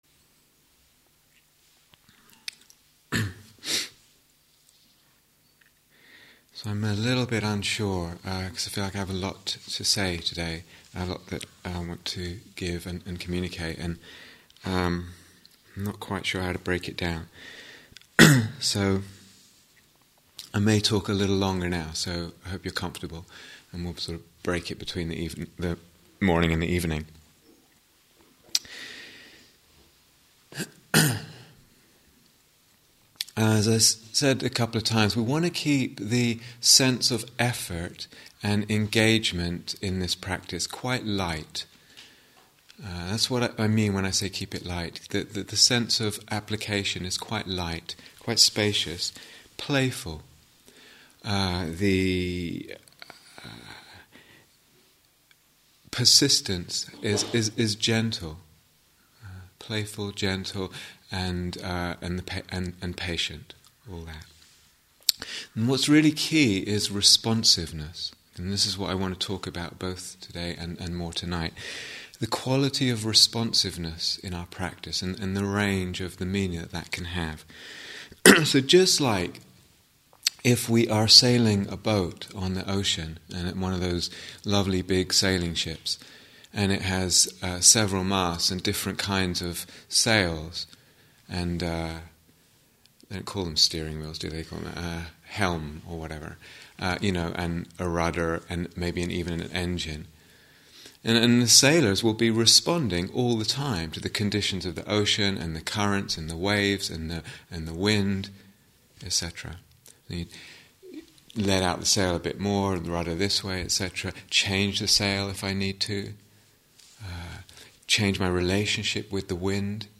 Mettā Instructions and Guided Meditation 2